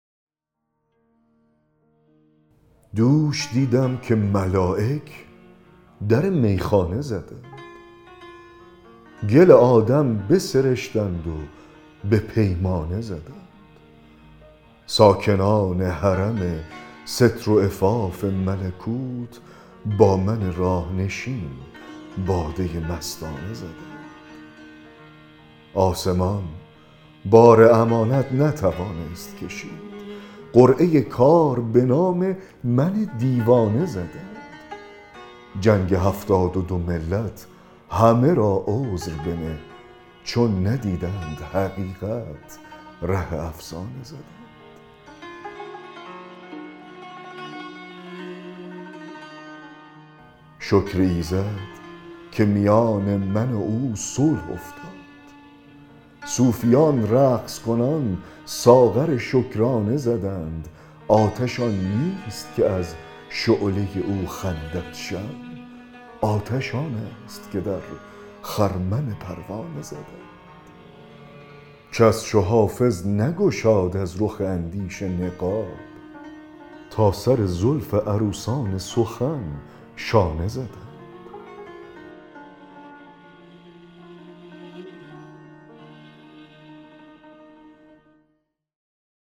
دکلمه غزل 184 حافظ
دکلمه-غزل-184-دوش-دیدم-که-ملایک-در-میخانه-زدند.mp3